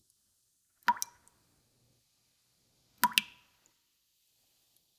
"text": "water drops",
Separate audio from [/tmp/tmpsv6f1jgcsample.wav] with textual query [water drops]